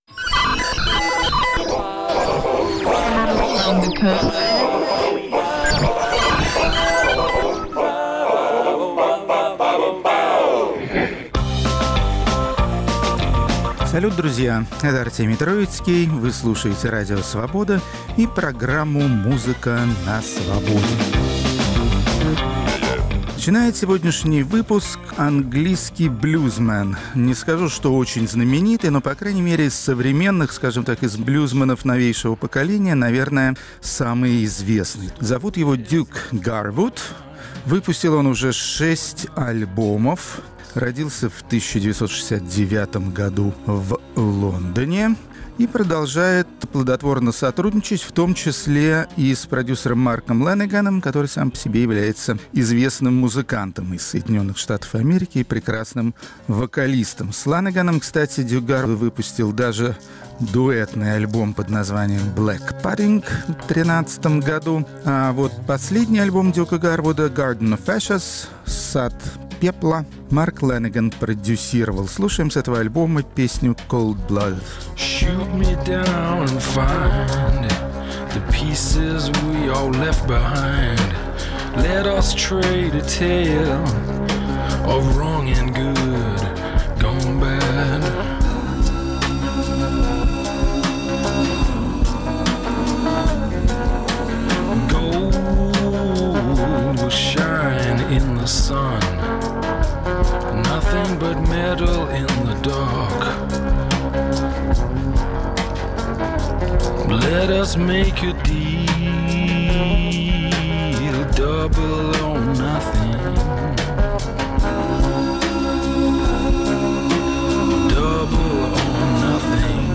Исполнители, работающие в стиле клезмер, это традиционная музыка восточноевропейских евреев. Рок-критик Артемий Троицкий изучает еврейское музыкальное наследие.